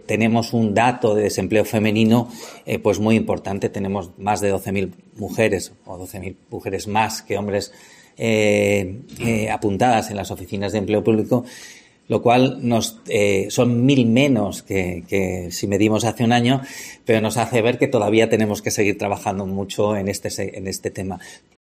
El director general de Política Económica es Javier Martínez, hace hincapié en el desempleo femenino